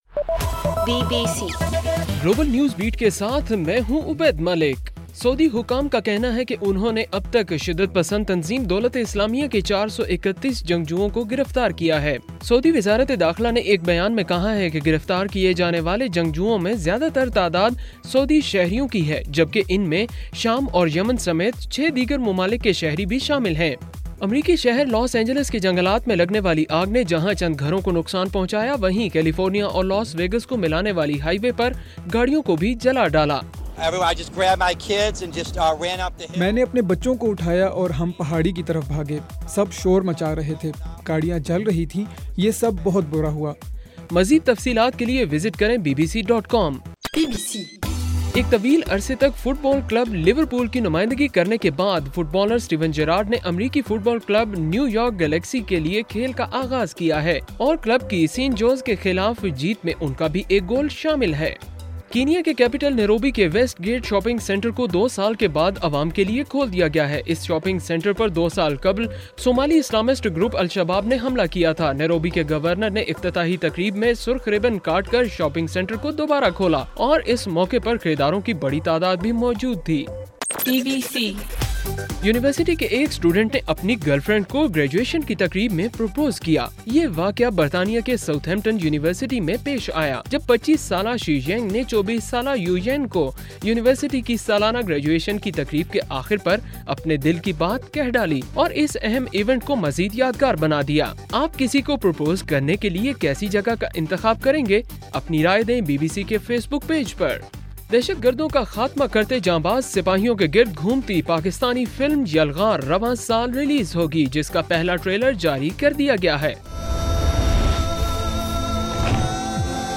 جولائی 18: رات 10 بجے کا گلوبل نیوز بیٹ بُلیٹن